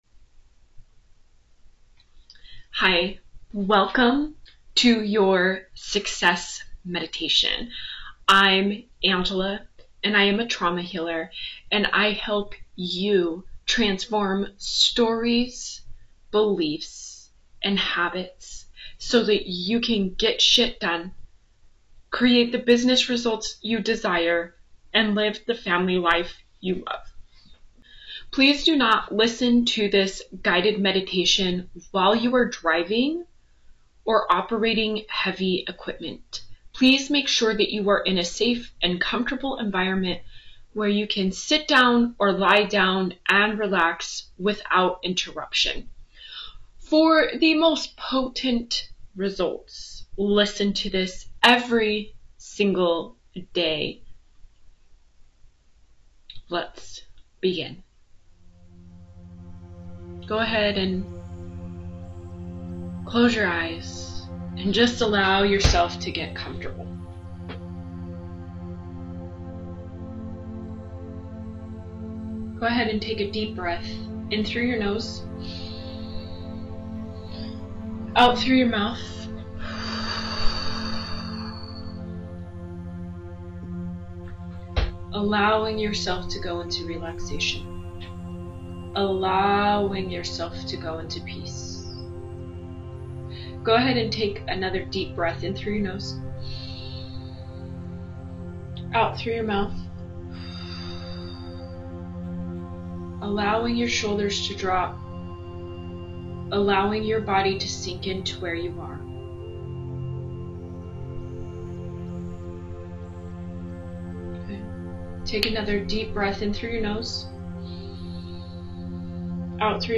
Visualize, embody & manifest the next 6 months exactly how you imagine • Visualize your success in 6 months • Action + healing = integration • Fill the gap between now & then • Success Meditation
success+meditation+edited.m4a